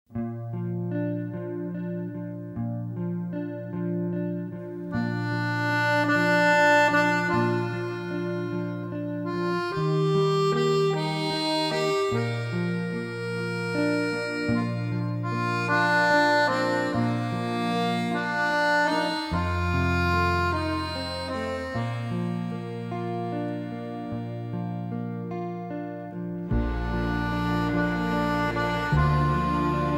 drums
bass
guitar
percussion
trumpet
trombone
saxophone
violins